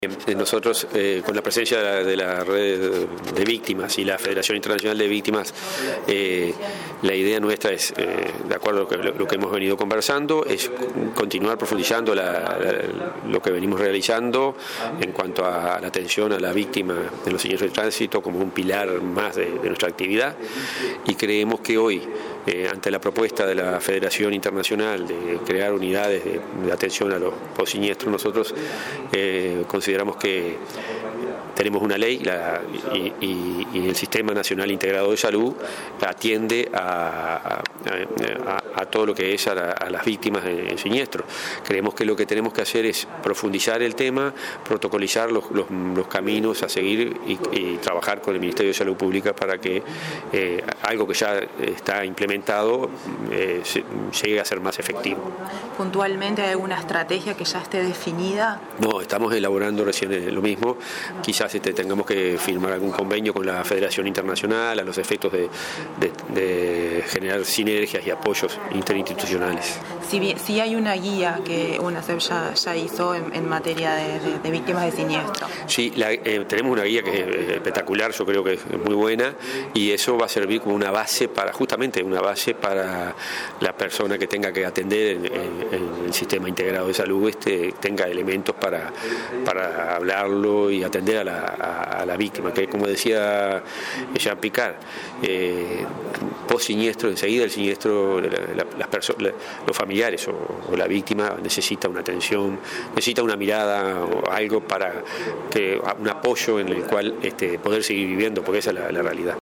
La Unidad Nacional de Seguridad Vial, Pronadis, la Red Nacional de Víctimas y Familiares de Siniestros de Tránsito junto a la Federación Iberoamericana de Asociaciones de Víctimas contra la Violencia Vial, anunciaron en Torre Ejecutiva, una estrategia de abordaje interinstitucional para las víctimas de siniestros de tránsito. El presidente de Unasev, Fernando Longo, sostuvo que se trabajará junto al MSP en tal sentido.